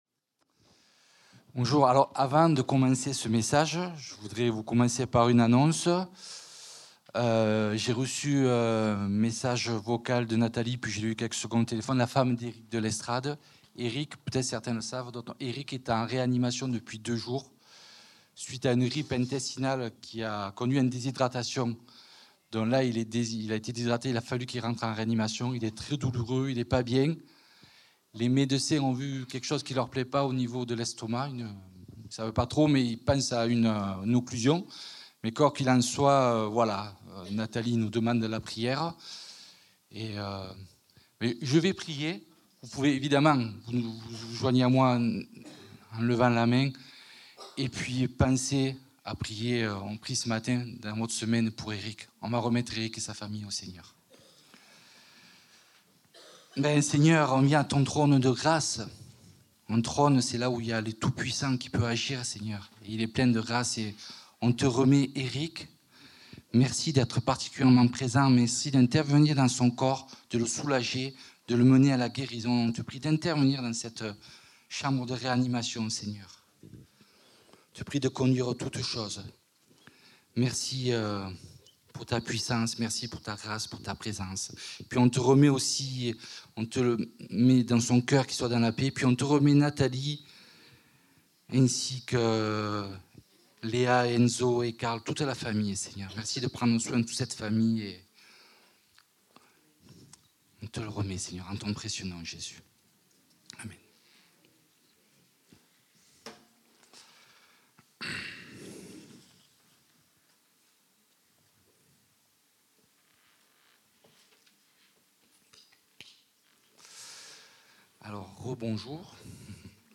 Culte du dimanche 28 décembre 2025, prédication